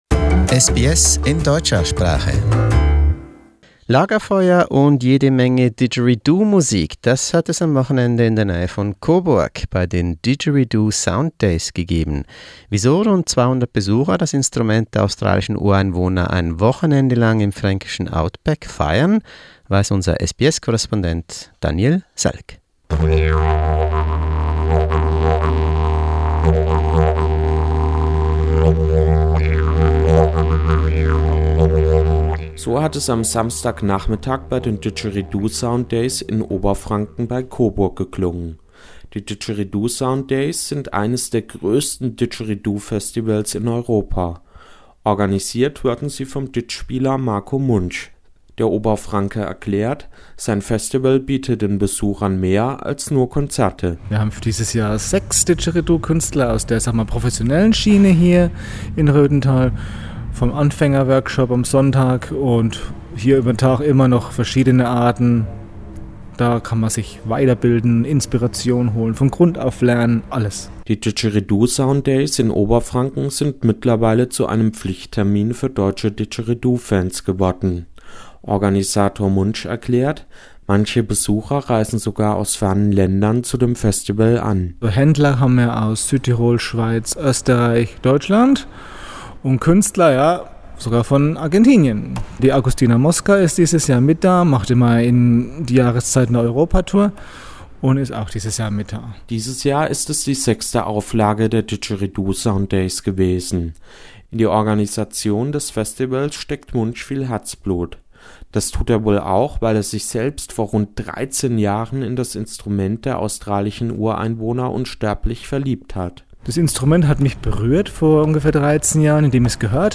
Didgeridoo Sound Days - in Bavaria!